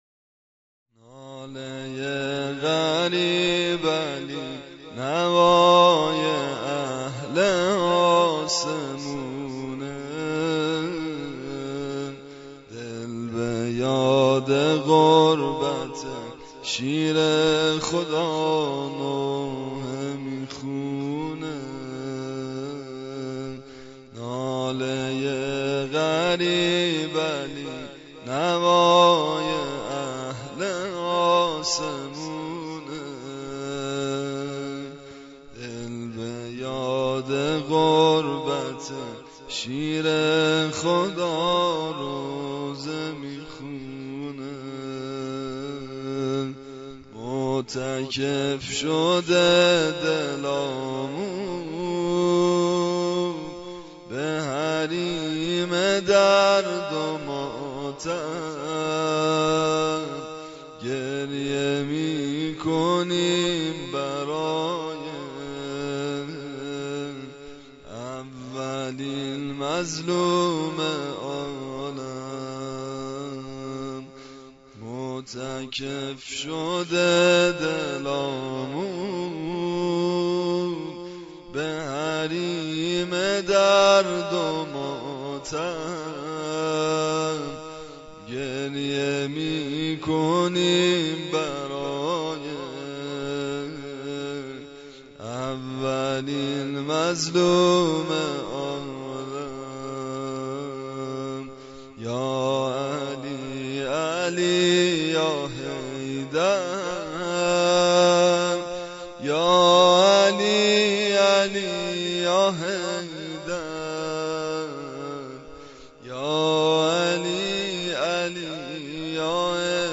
نوحه واحد و شور برای امام علی ابن ابی طالب (ع) -( ناله ی غریب علی ، نوای اهل آسمونه )